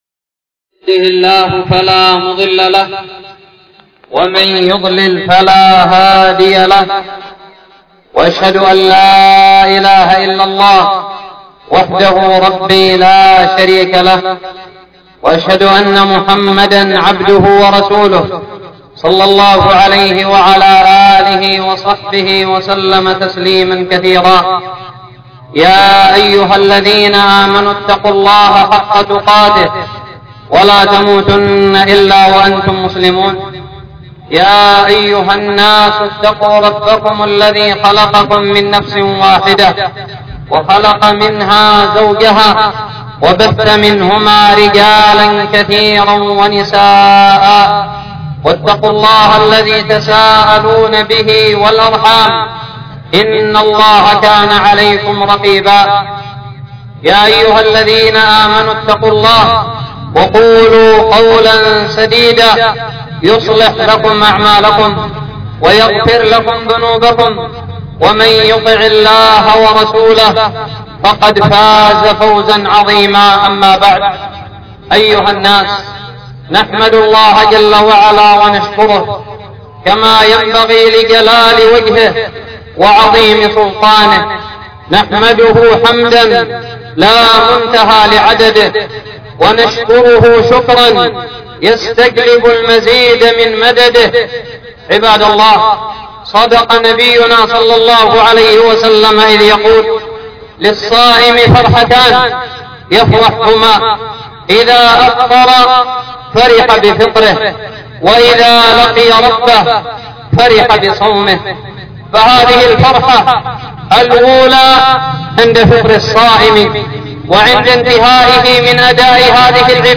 خطبة عيد الفطر 72